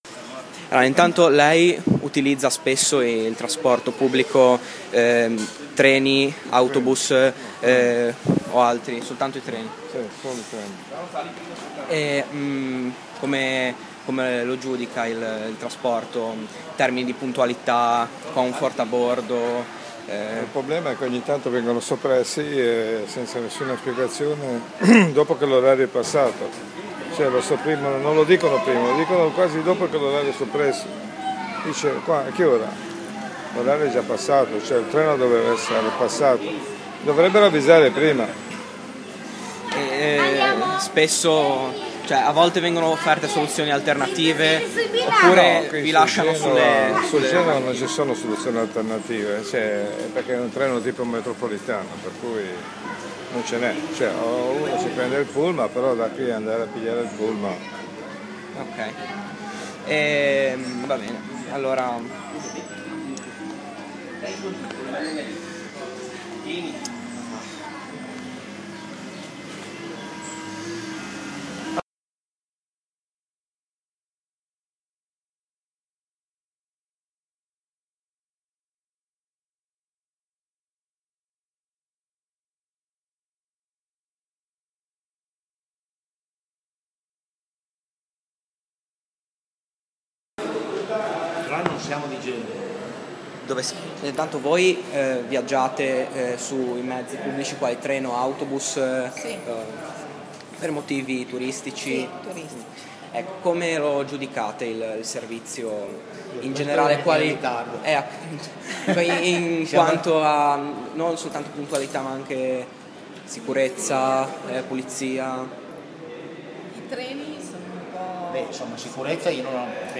Intervista a diversi utenti del trasporto pubblico, perlopiù turisti
play_circle_filled Intervista a diversi utenti del trasporto pubblico, perlopiù turisti Radioweb Mazzini / intervista del 06/08/2014 raccolta la vox populi per l'inchiesta sui trasporti che sto conducendo.